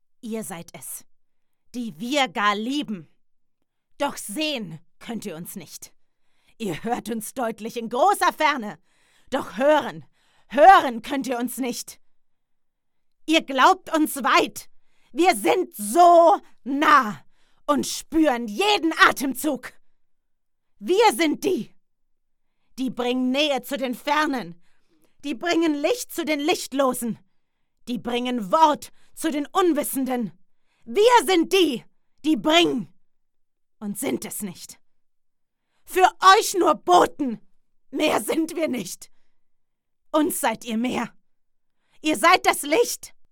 aggressiv download file >>